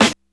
DrSnare22.WAV